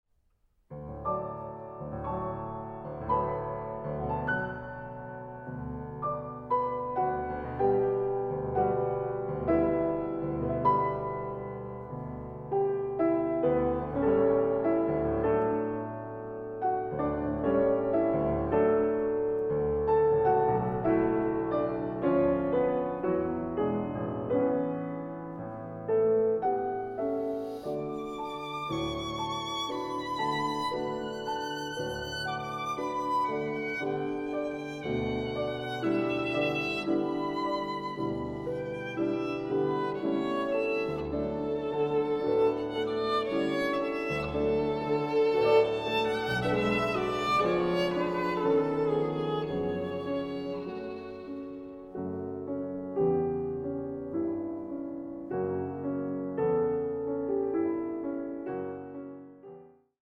Sonatas for Piano and Violin
Piano
Violin